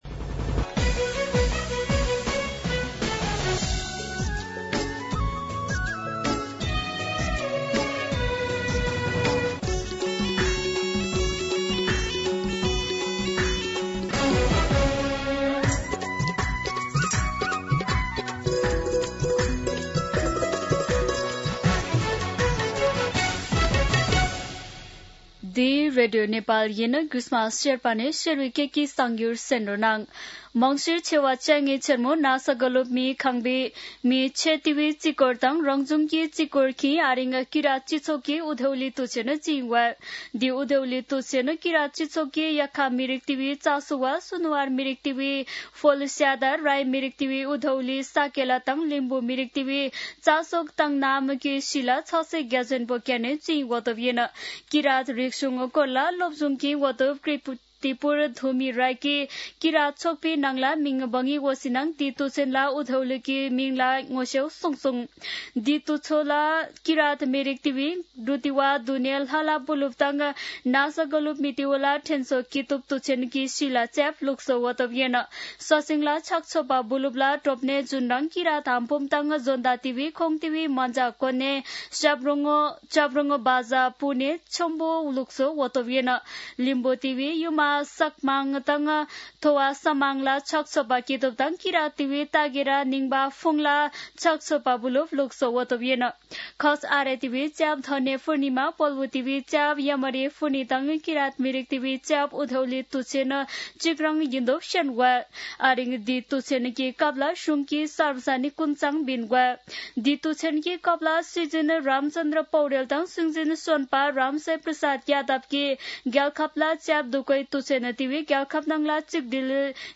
शेर्पा भाषाको समाचार : १८ मंसिर , २०८२
Sherpa-News.mp3